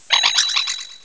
indeedee_female.aif